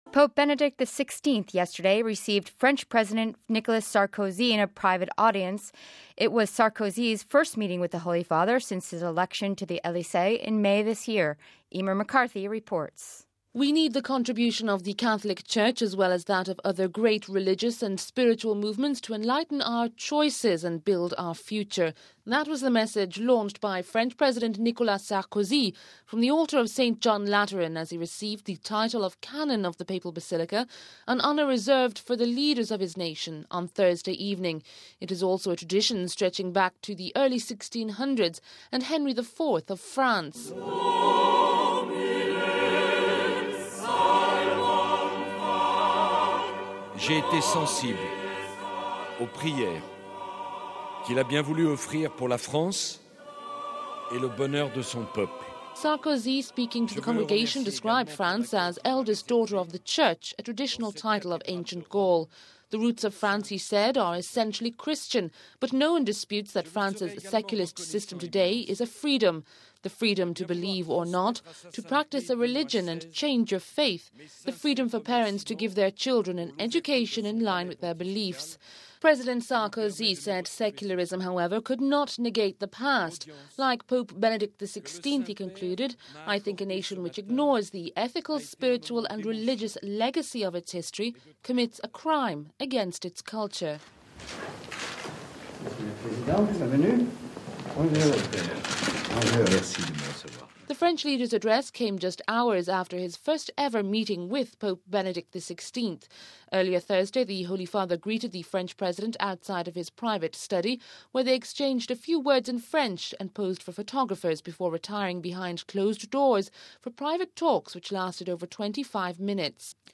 French President Speaks at Basilica of St. John Lateran